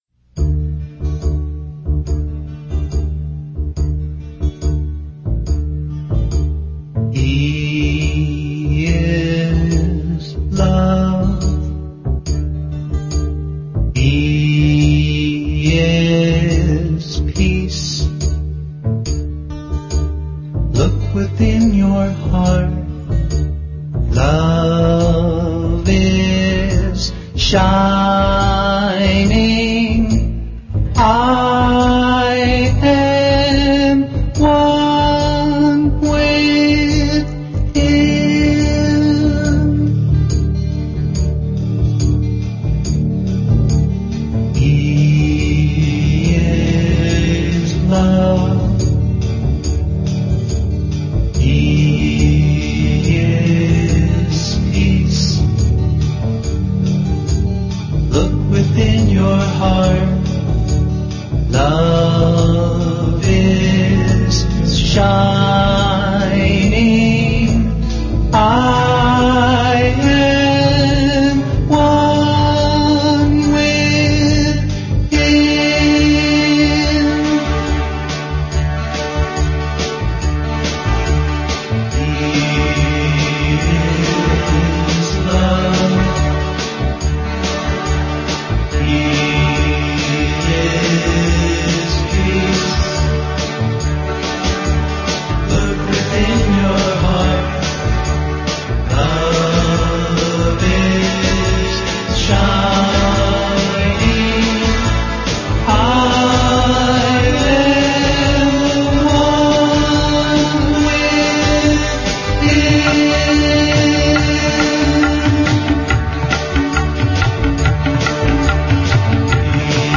1. Devotional Songs
Major (Shankarabharanam / Bilawal)
8 Beat / Keherwa / Adi
3 Pancham / E
7 Pancham / B